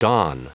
Prononciation du mot dawn en anglais (fichier audio)
Prononciation du mot : dawn